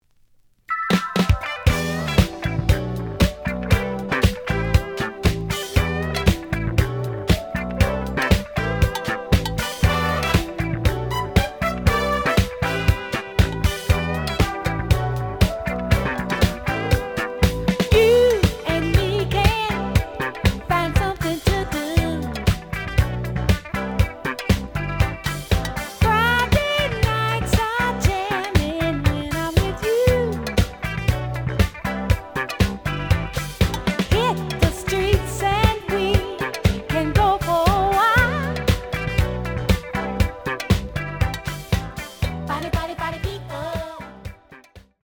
The audio sample is recorded from the actual item.
●Genre: Disco
Some click noise on later half on A side, but almost good.)